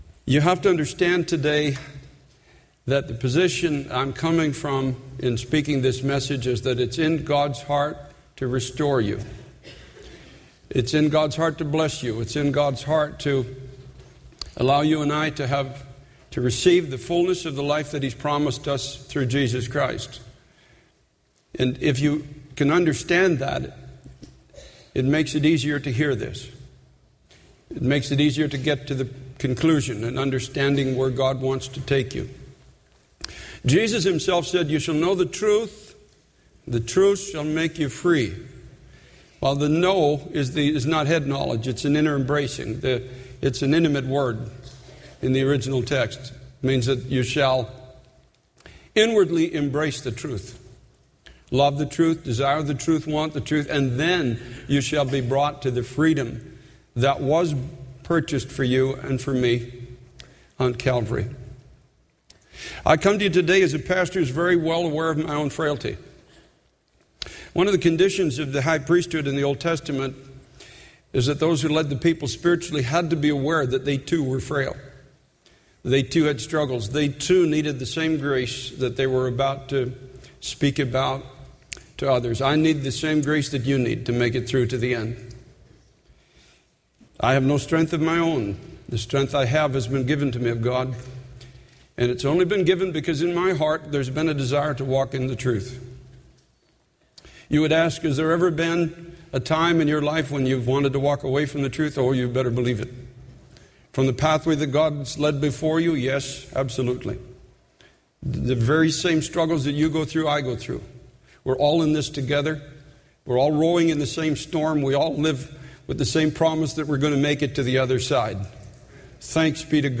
In this sermon, the preacher emphasizes the importance of living a transformed life in Christ. He highlights that religion, when devoid of true faith and relationship with God, is empty and dead.